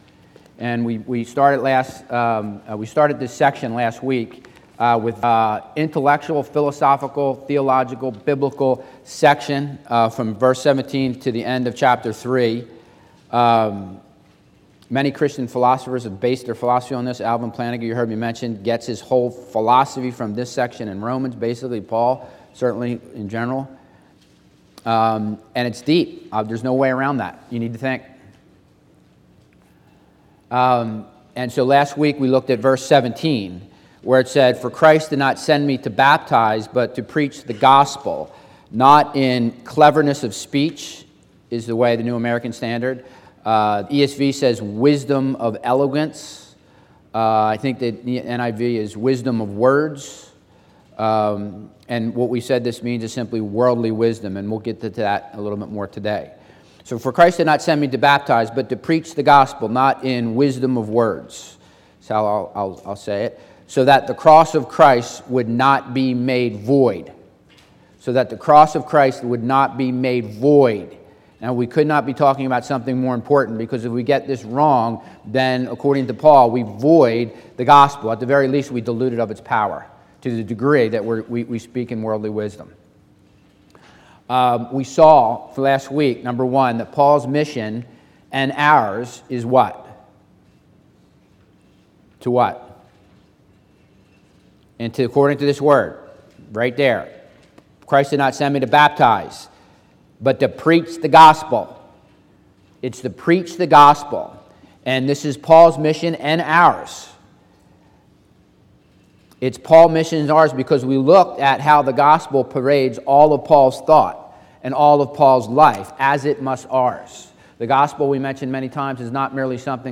Bible Text: 1 Corinthians 1:18-25 | Preacher